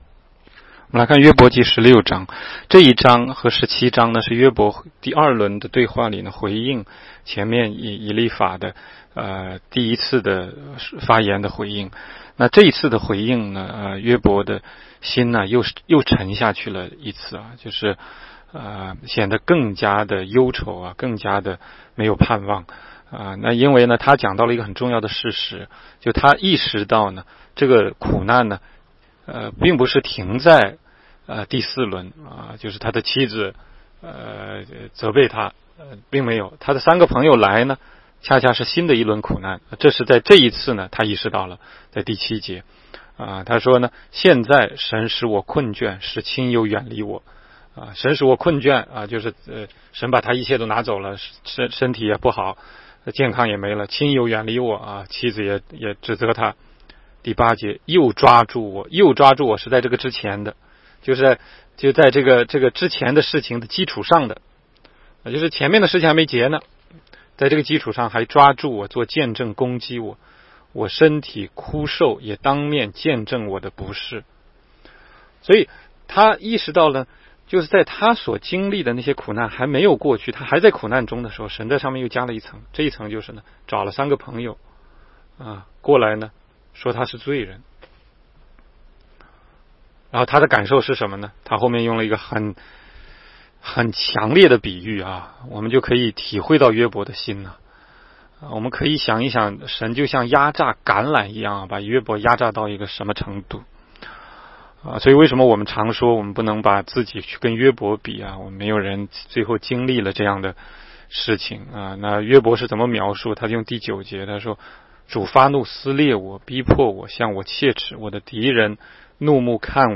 16街讲道录音 - 每日读经-《约伯记》16章